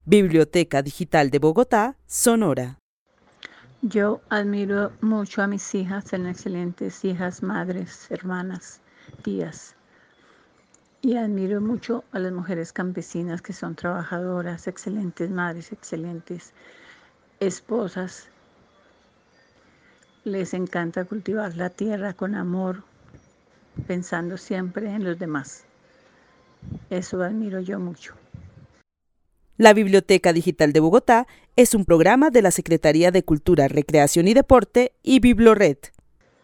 Narración oral de una mujer que vive en la ciudad de Bogotá, quien admira a sus hijas, hermanas y especialmente a las mujeres campesinas, a quienes considera excelentes trabajadoras cultivando la tierra con amor, como madres y esposas. El testimonio fue recolectado en el marco del laboratorio de co-creación "Postales sonoras: mujeres escuchando mujeres" de la línea Cultura Digital e Innovación de la Red Distrital de Bibliotecas Públicas de Bogotá - BibloRed.